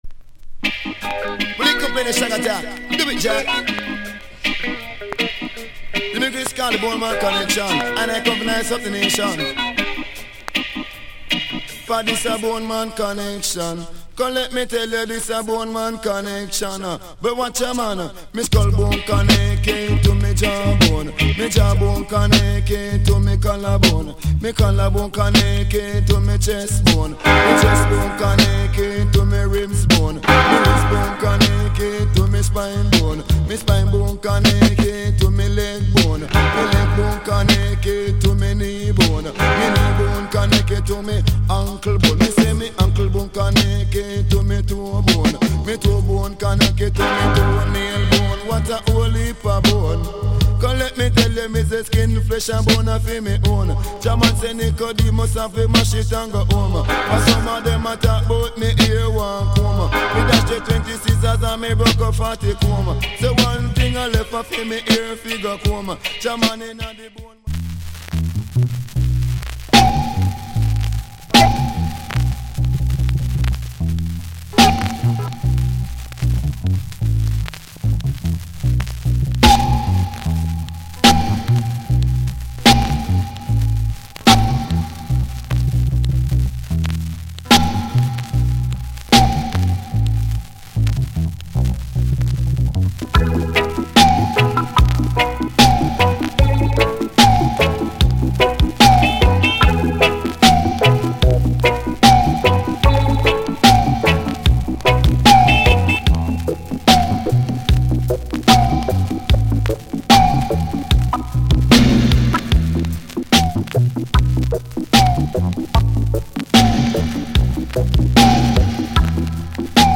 80's Big DJ Classic